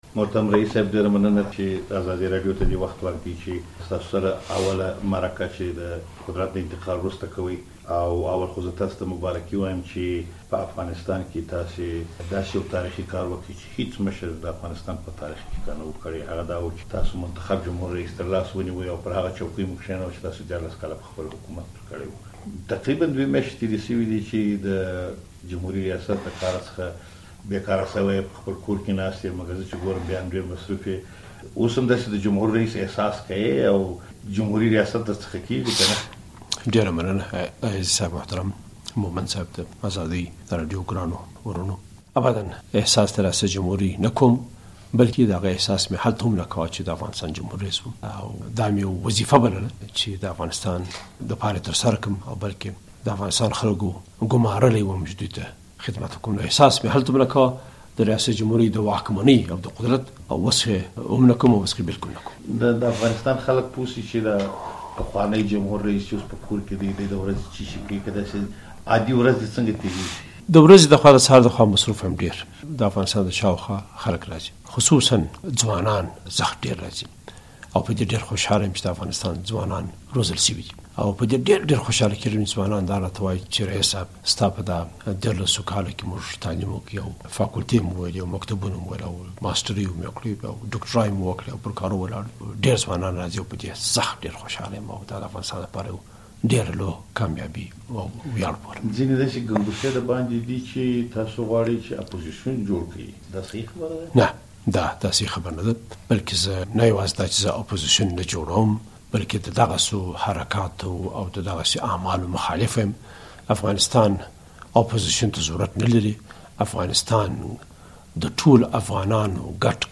له حامد کرزي سره مرکه